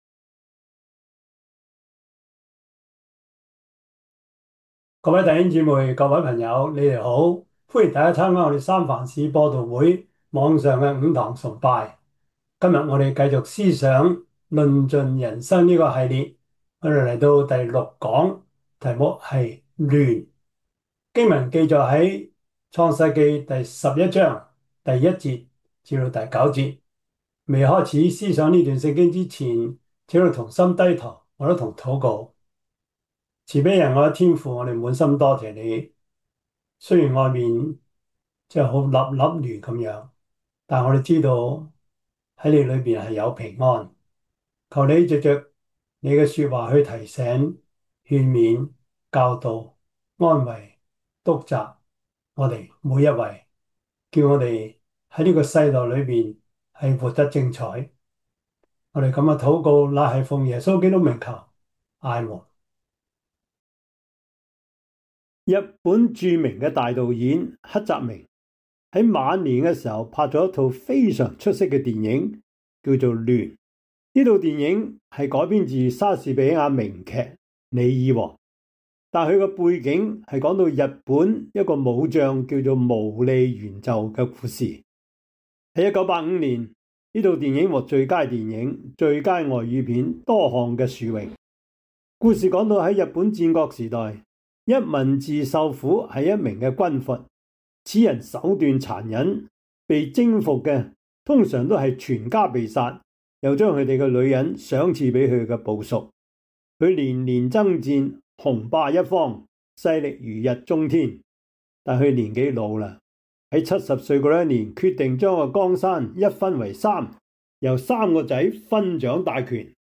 創世記 11:1-9 Service Type: 主日崇拜 創世記 11:1-9 Chinese Union Version